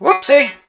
barley_throw_02.wav